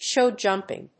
アクセントshów jùmping